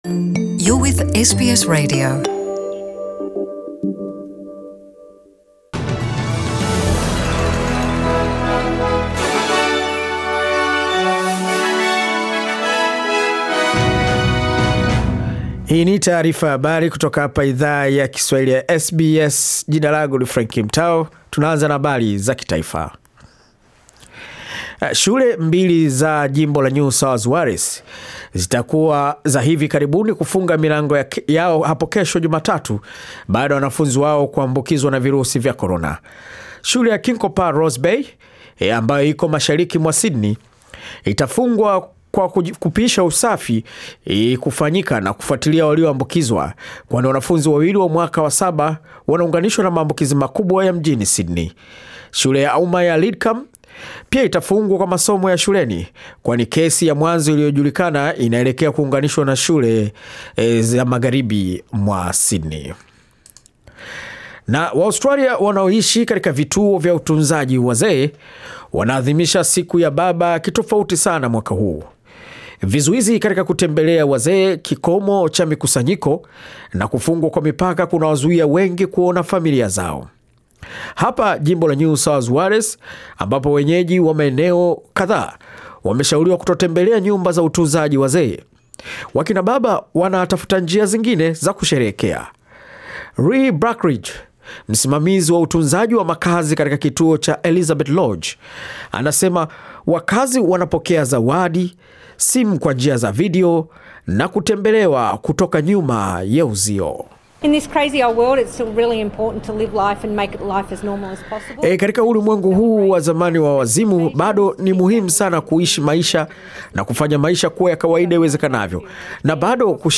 Taarifa ya habari 6 Septemba